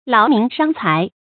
láo mín shāng cái
劳民伤财发音
成语正音劳，不能读作“lāo”。